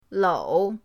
lou3.mp3